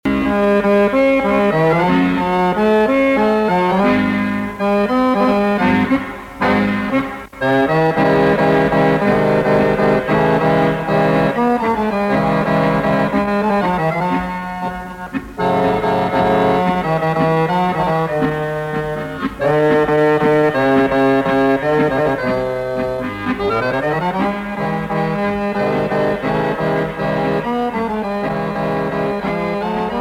accordion
tango